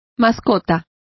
Complete with pronunciation of the translation of mascot.